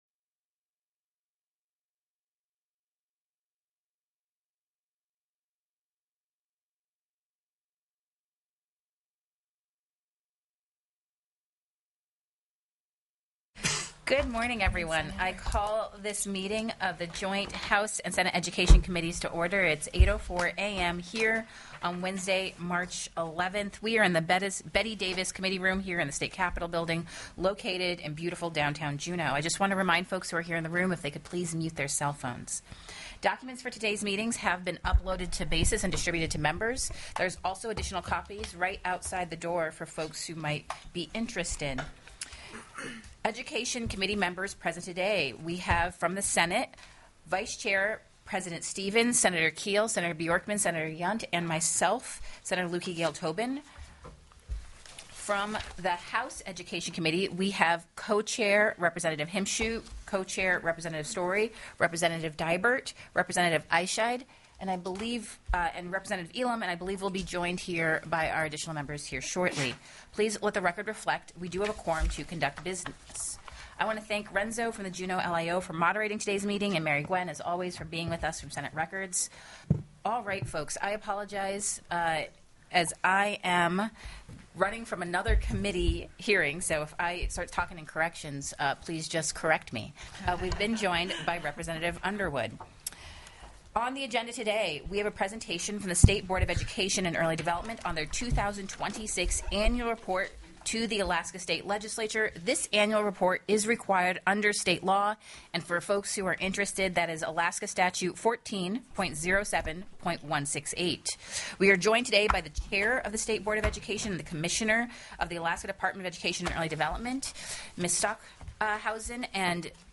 The audio recordings are captured by our records offices as the official record of the meeting and will have more accurate timestamps.
+ Meeting jointly with the House Education TELECONFERENCED Committee Presentation: 2026 State Board of Education Report to the Alaska Legislature by Deena Bishop, Commissioner, Alaska Department of Education and Early Development